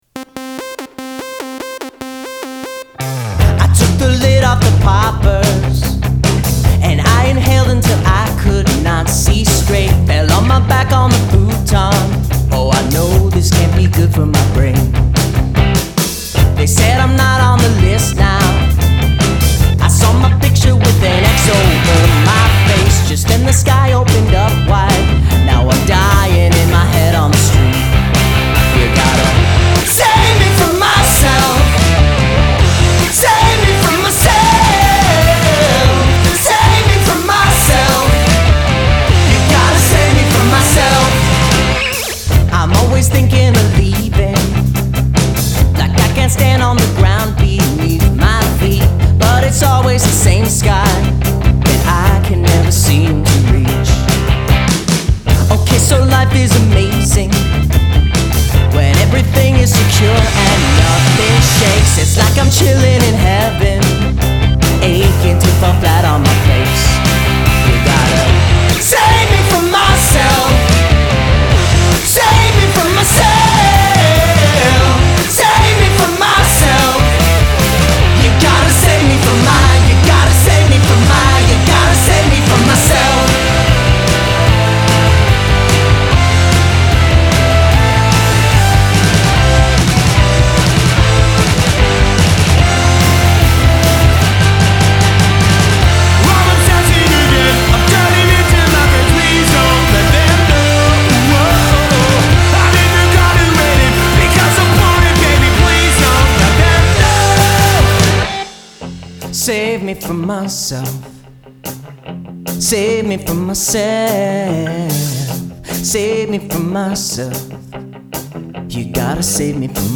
Additional percussion